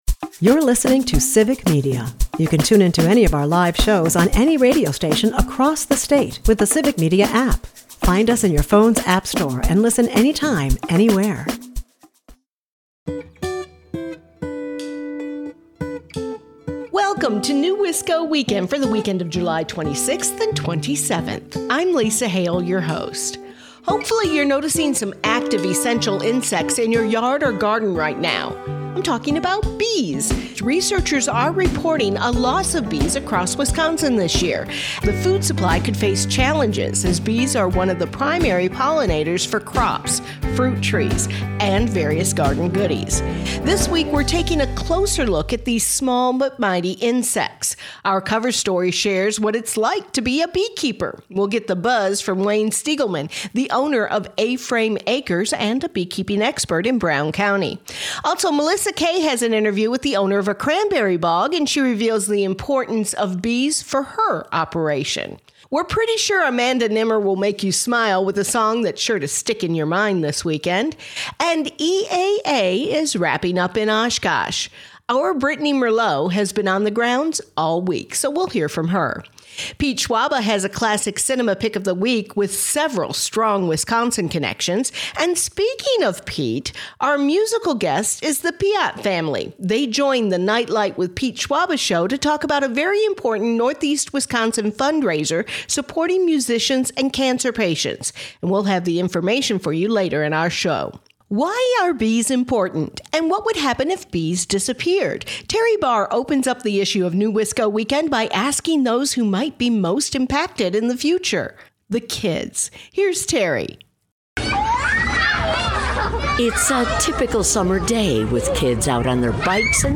We have fun features all about bees, including a musical commentary.
NEWisco Weekend is a part of the Civic Media radio network and airs Saturdays at 8 am and Sundays at 11 am on 97.9 WGBW and 98.3 and 96.5 WISS.